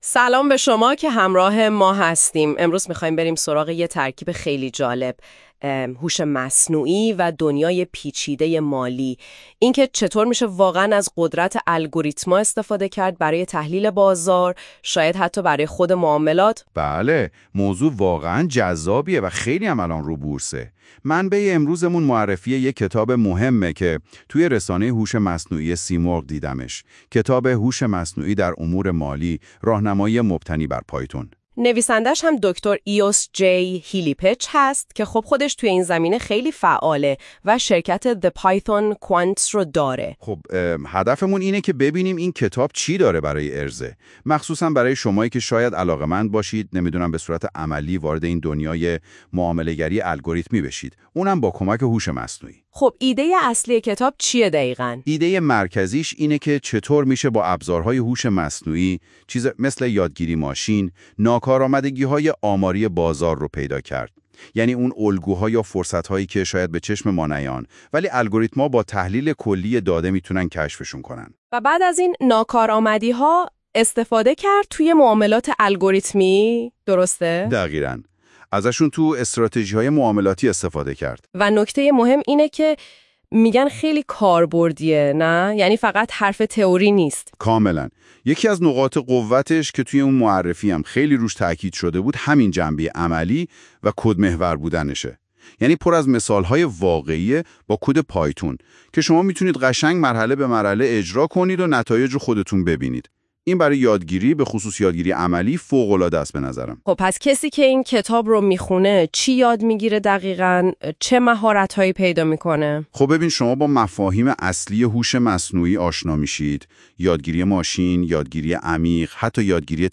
فایل صوتی معرفی کتاب: این فایل بر اساس اطلاعات همین مطلب توسط هوش مصنوعی ایجاد شده و اشتباهات تلفظی داره اما شنیدنش برای دوستانی که حوصله مطالع کتاب را ندارند خیلی مفیده.